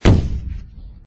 bomb.ogg